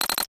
NOTIFICATION_Metal_07_mono.wav